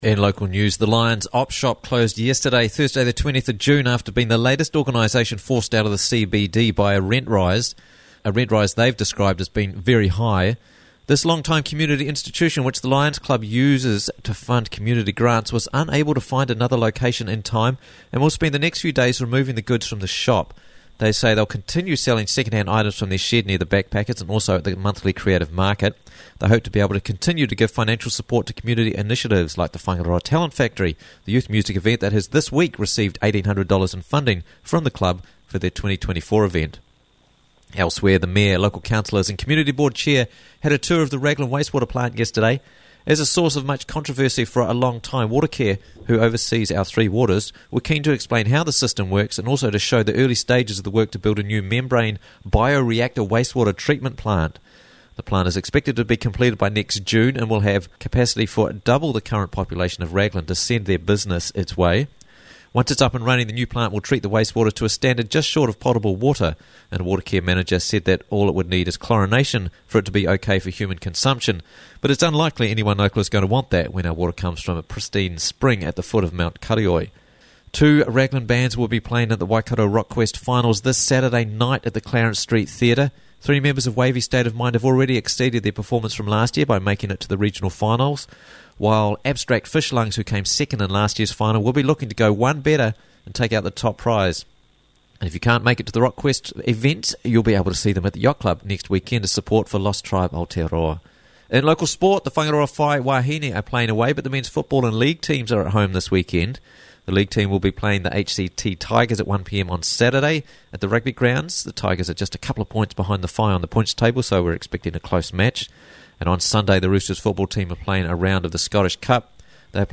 Raglan News Friday 21st June 2024 - Raglan News Bulletin